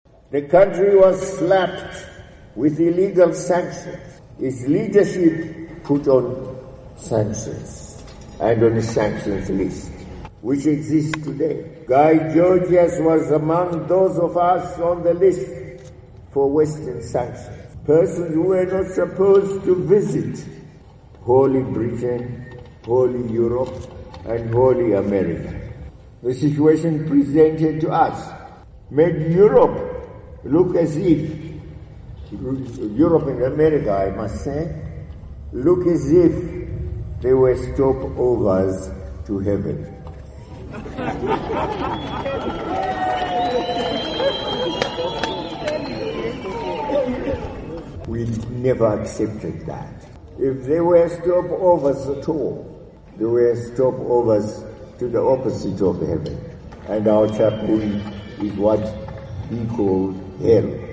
Mr. Mugabe made the remarks at the National Heroes Acre on Tuesday where Georgias, who filed a suit to block the sanctions, was laid to rest.
President Mugabe Addressing Zimbabweans At Heroes Acre